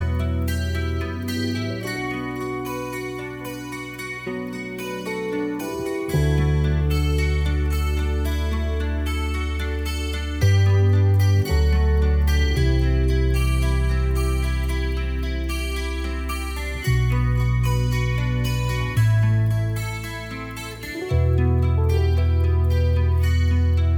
No Electric Guitars Rock 4:39 Buy £1.50